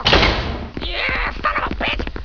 doors